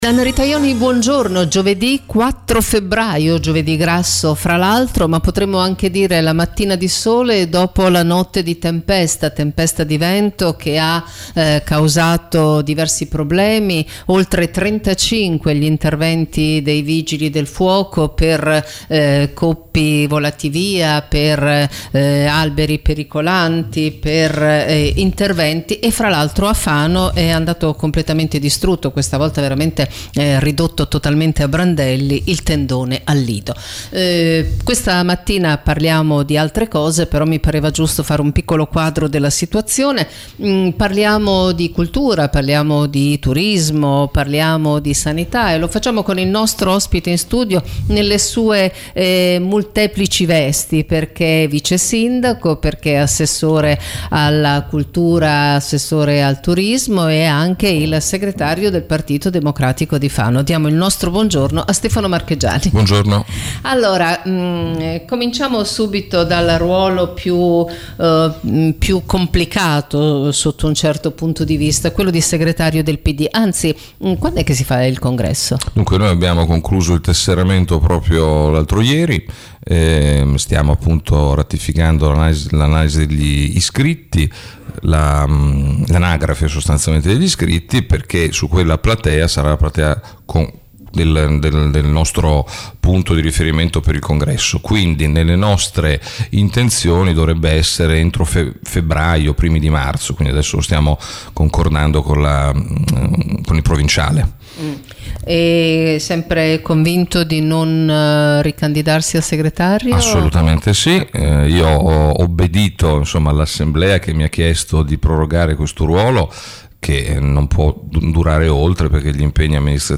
Dalla sanità al carnevale, agli interventi al museo, rocca malatestiana e molto altro. Intervista all’assessore Stefano Marchegiani. ascolta